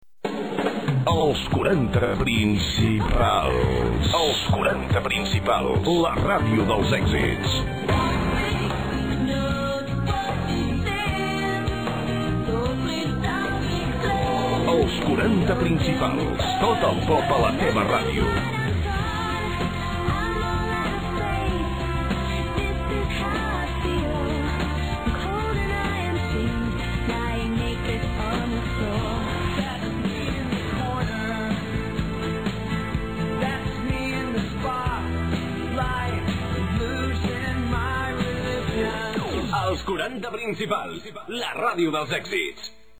Identificacions i eslògan de l'emissora
Musical
FM